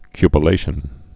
(kypə-lāshən)